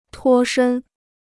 脱身 (tuō shēn): sich befreien; entkommen.